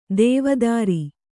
♪ dēvadāri